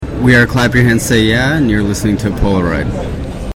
Jingle dell'anno